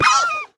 1.21.5 / assets / minecraft / sounds / mob / fox / death2.ogg
death2.ogg